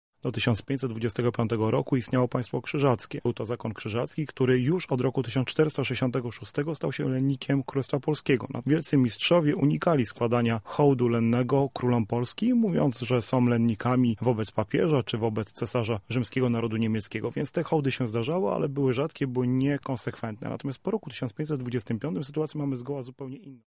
historyk z Katolickiego Uniwersytetu Lubelskiego